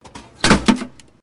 vending machine.ogg